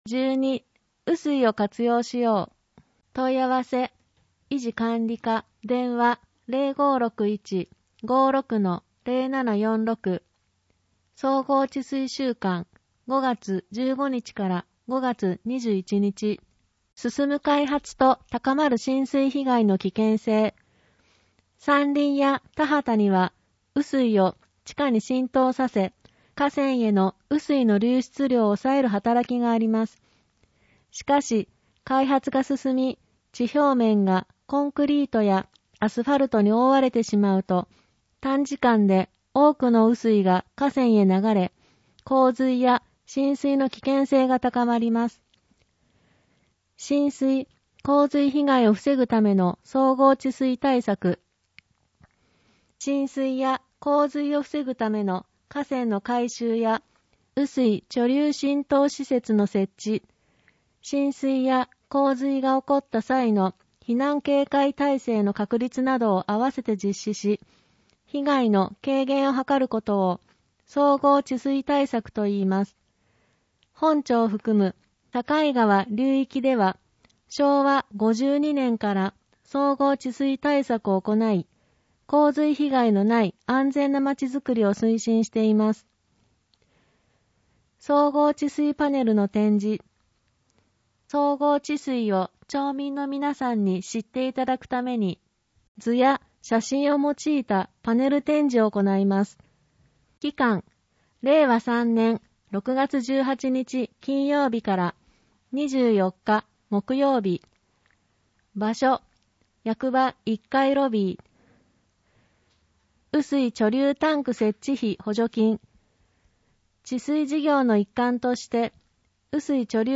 広報とうごう音訳版（2021年5月号）